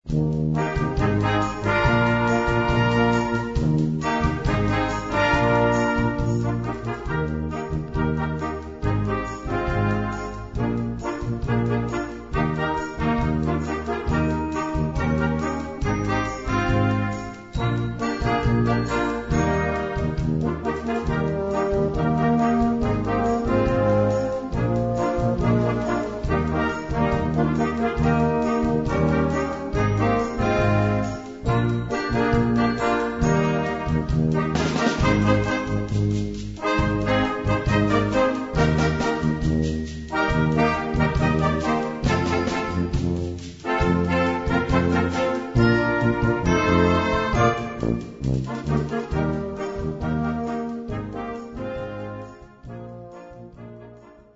Kategorie Blasorchester/HaFaBra